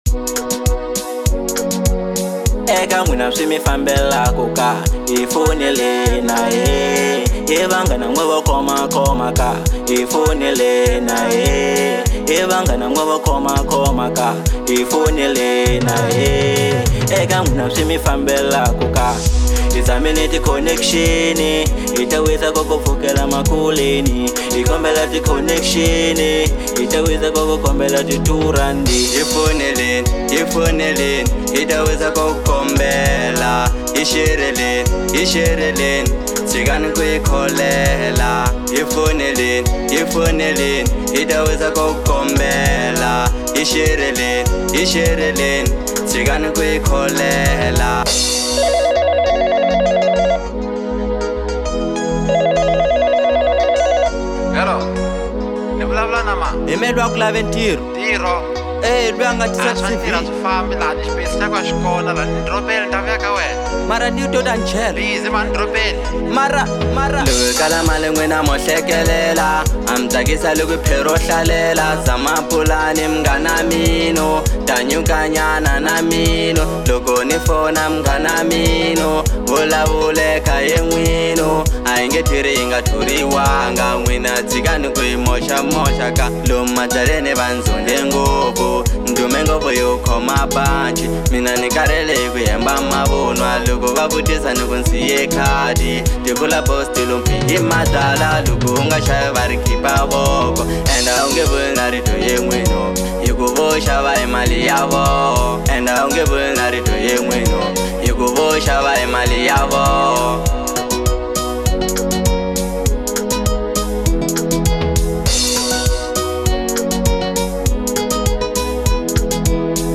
03:30 Genre : Marrabenta Size